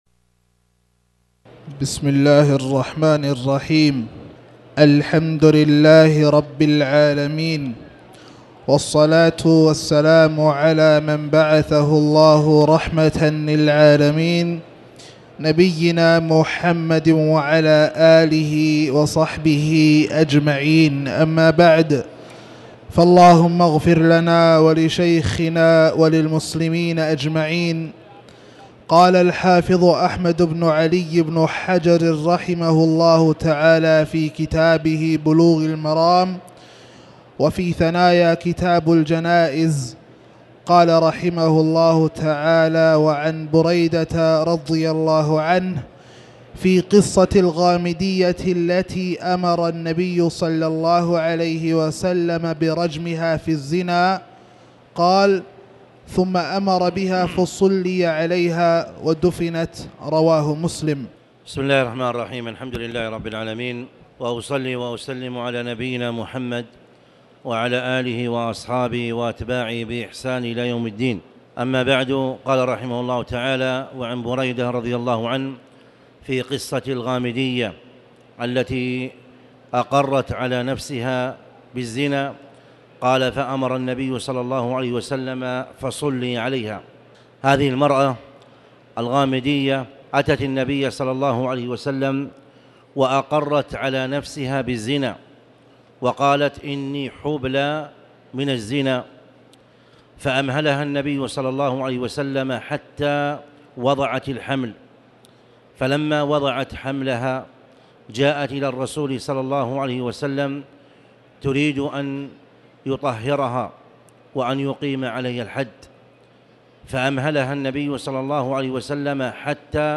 تاريخ النشر ٢٩ جمادى الأولى ١٤٣٩ هـ المكان: المسجد الحرام الشيخ